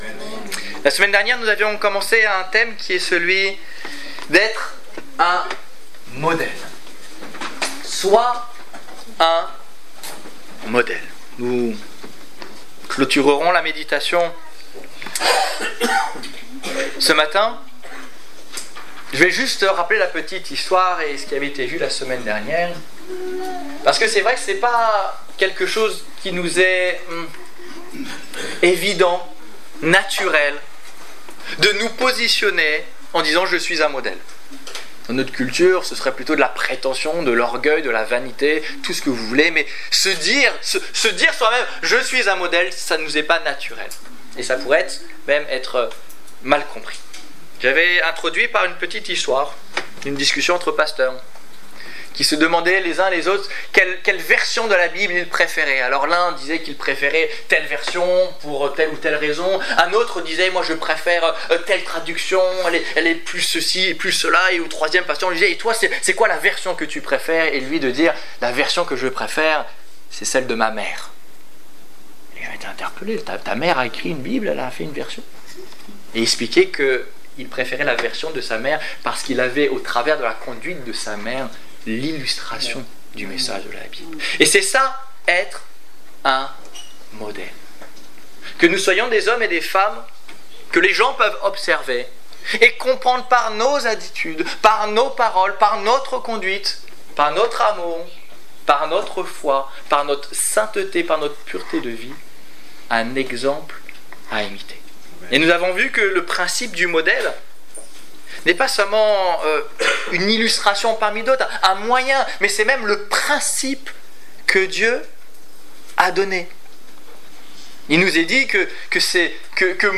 Exhortation - Culte du 21 février 2016